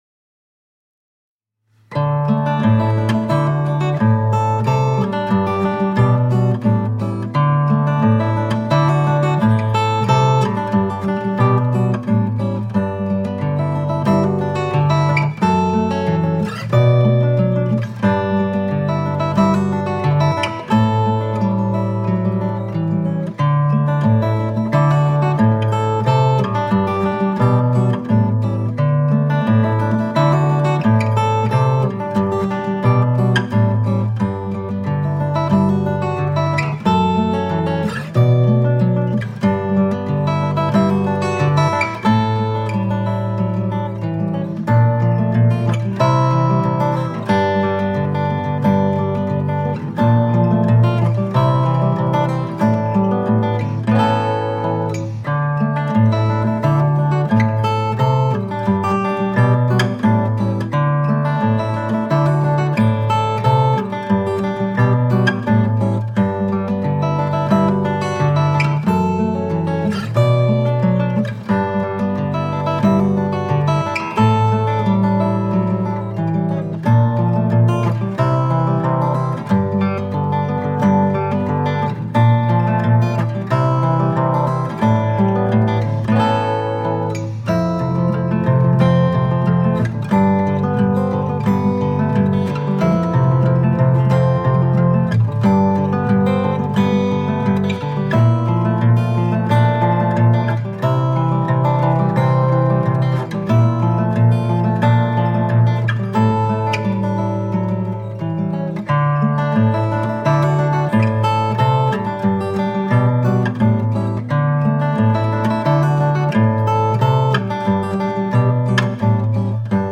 Filmic composer and acoustic fingerstyle guitarist.